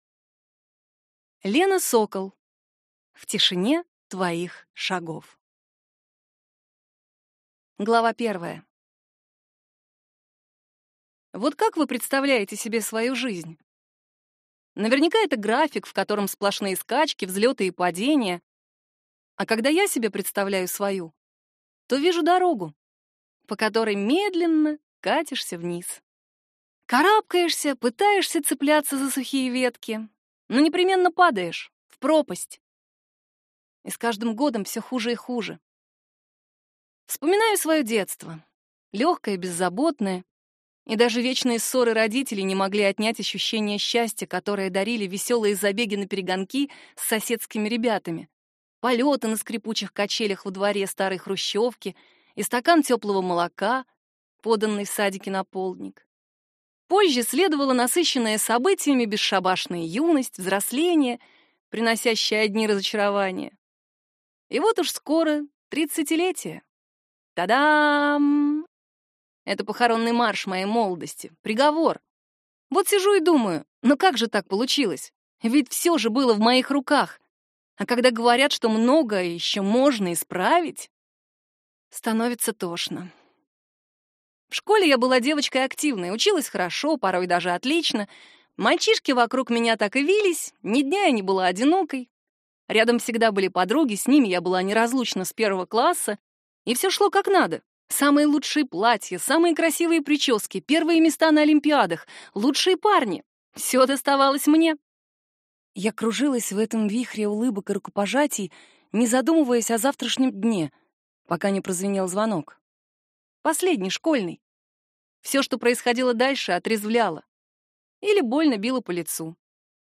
Аудиокнига В тишине твоих шагов | Библиотека аудиокниг